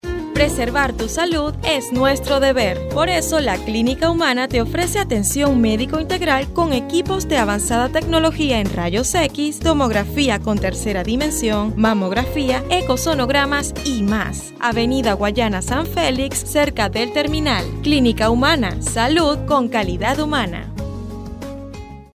Sprechprobe: Werbung (Muttersprache):
I really like the imitations of characters, I have good diction, pronunciation, voice projection and work with a neutral accent in Spanish and English.